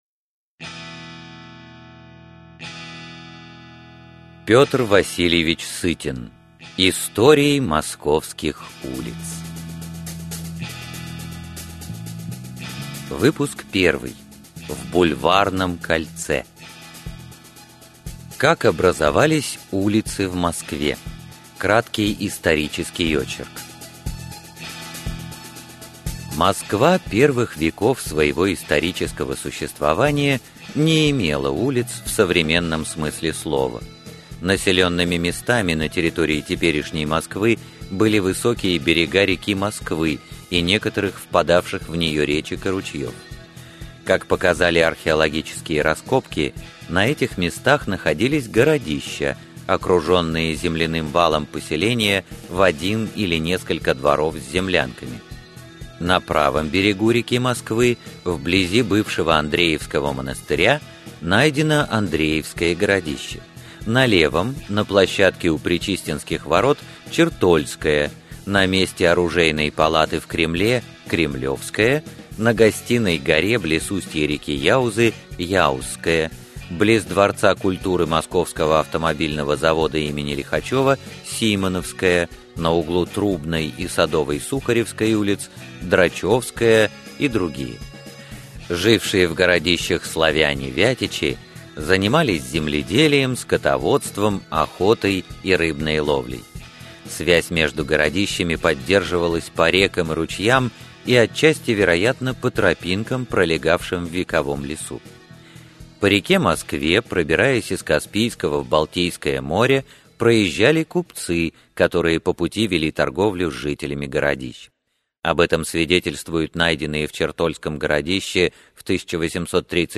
Аудиокнига Истории московских улиц. Выпуск 1 | Библиотека аудиокниг